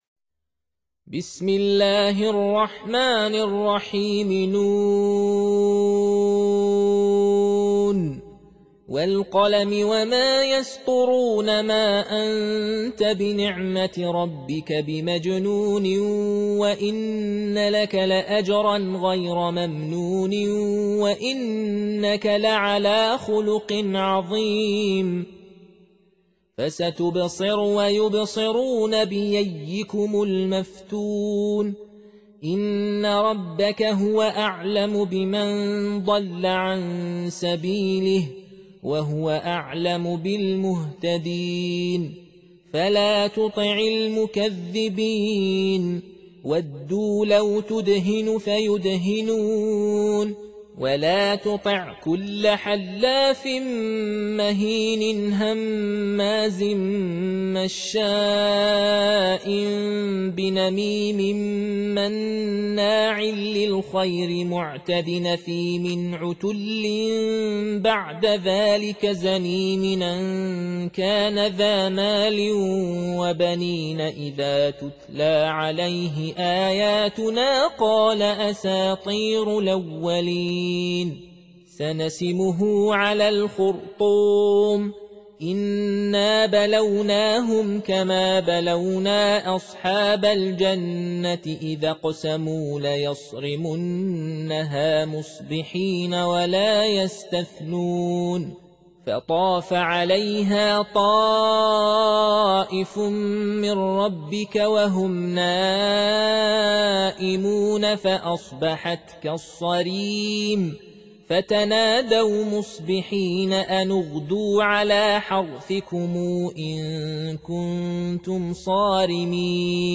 Surah Repeating تكرار السورة Download Surah حمّل السورة Reciting Murattalah Audio for 68. Surah Al-Qalam سورة القلم N.B *Surah Includes Al-Basmalah Reciters Sequents تتابع التلاوات Reciters Repeats تكرار التلاوات